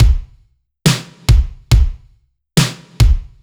Index of /musicradar/french-house-chillout-samples/140bpm/Beats
FHC_BeatC_140-01_KickSnare.wav